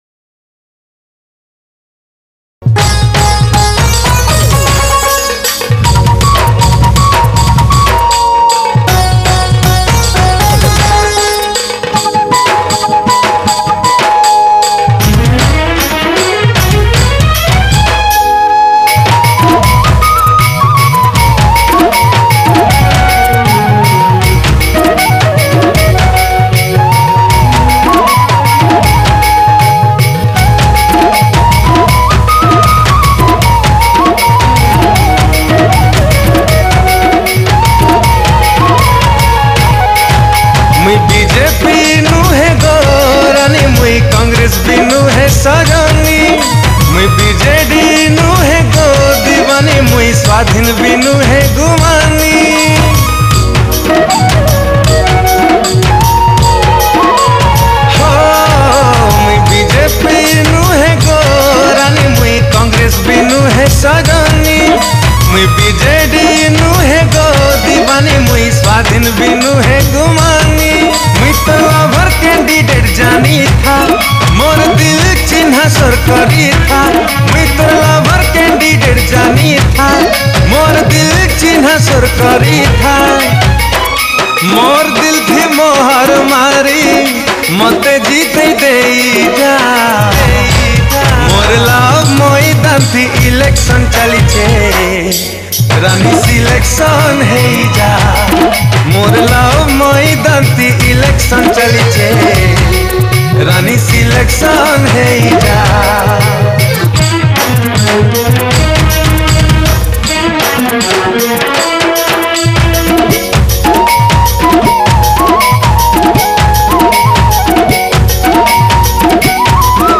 Sambalpuri Modern Song